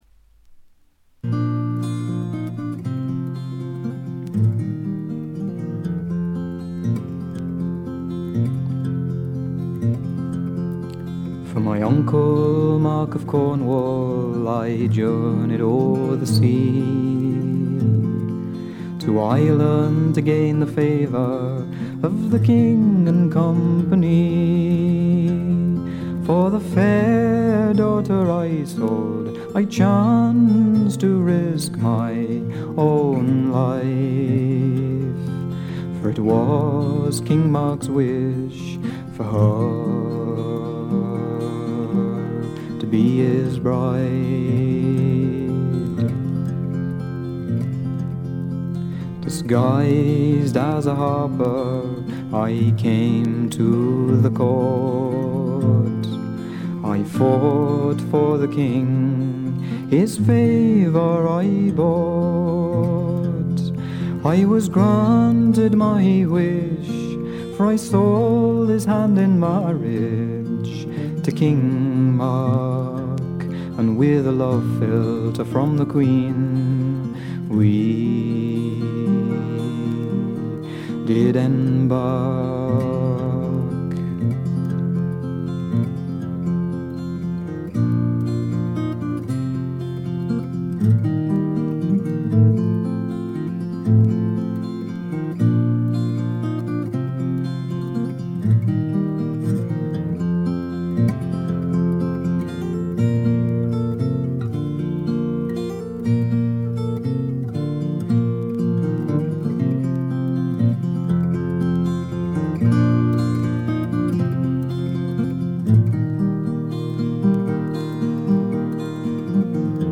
微細なチリプチわずか。
自身のギター、ダルシマー、ツィターの他はコンサーティナ兼笛の奏者が付くだけの極めてシンプルな演奏を聴かせます。
ギターにしてもびしびし決めるような奏法ではなく、とても優しい音色を響かせています。
まるで静寂そのものを聴かせるような、静謐で至上の美しさをたたえた作品です。
試聴曲は現品からの取り込み音源です。
Recorded At - Tonstudio St. Blasien